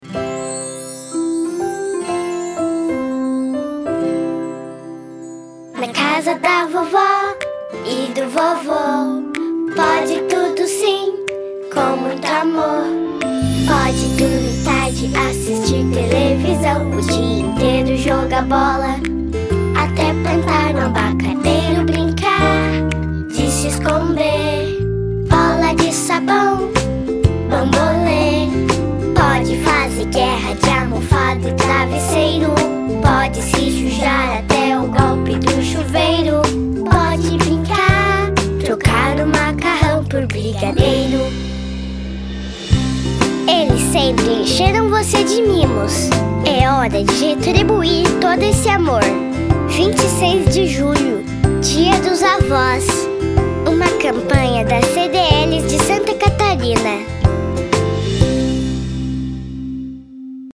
Jingle 1 Download